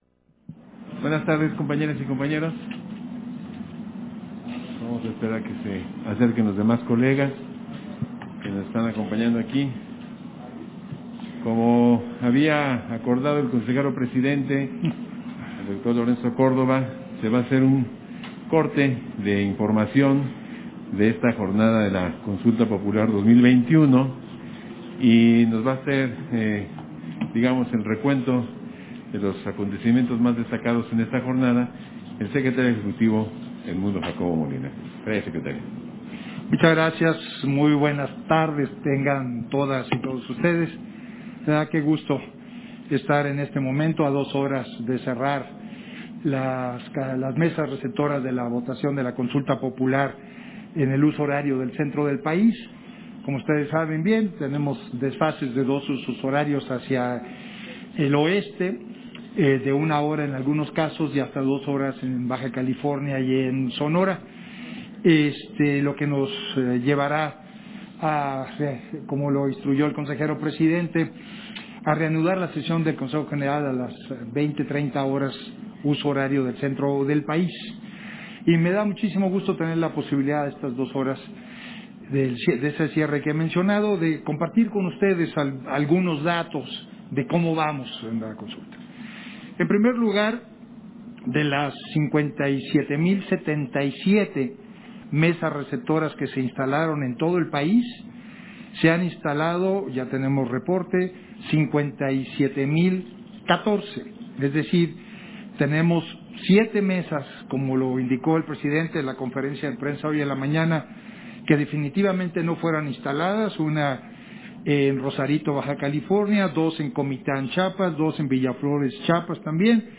010821_AUDIO_CONFERENCIA-DE-PRENSA-CONSULTA-POPULAR-SECRETARIO-EJECUTIVO
Versión estenográfica de la conferencia de prensa del Secretario General, Edmundo Jacobo Molina, en el marco de la consulta popular